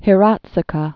(hĭ-rätsə-kä, hērä-tskä)